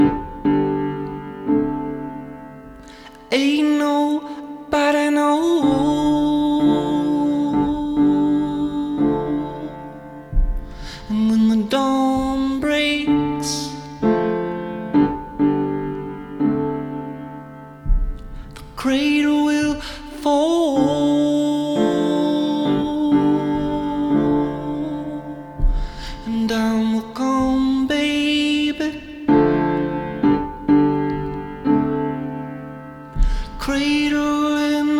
# Альтернатива